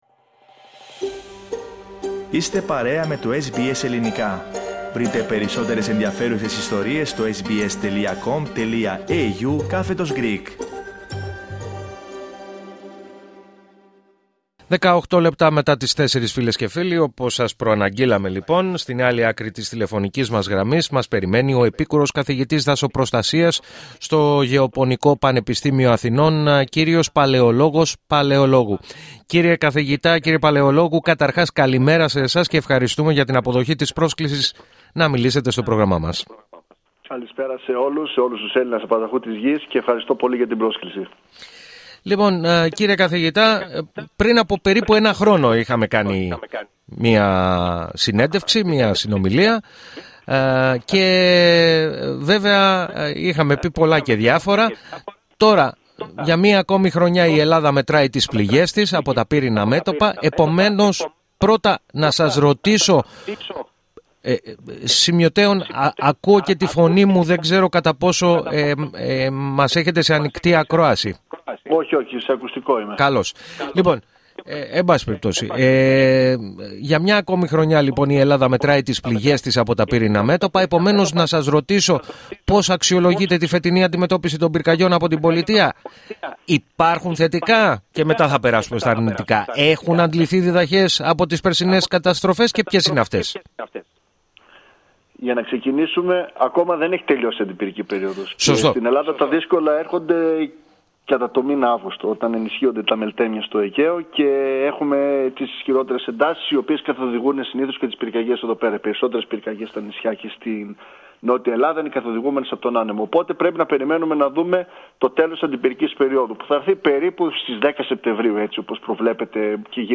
Ακούστε ολόκληρη τη συνέντευξη, πατώντας το σύμβολο στο μέσο της κεντρικής φωτογραφίας.